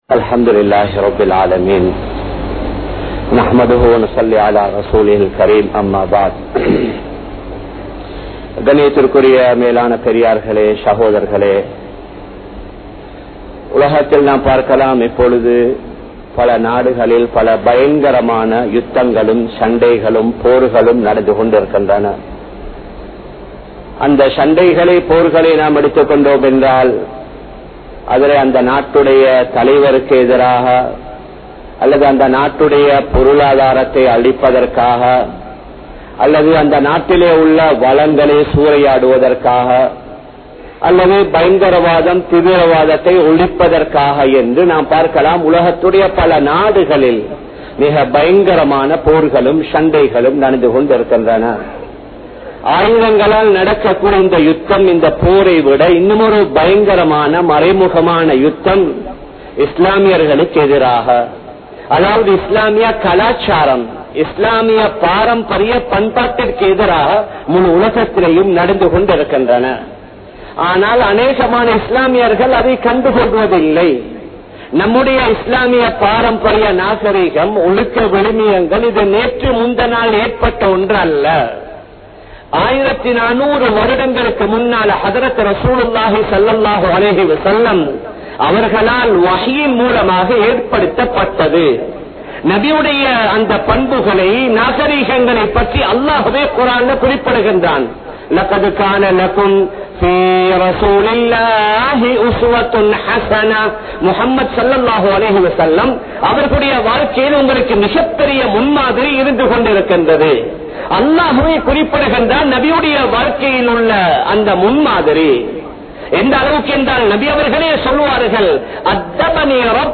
Naveena Ulahaththitku Adimaiyaahum Muslimkal (நவீன உலகத்திற்கு அடிமையாகும் முஸ்லிம்கள்) | Audio Bayans | All Ceylon Muslim Youth Community | Addalaichenai
Colombo 03, Kollupitty Jumua Masjith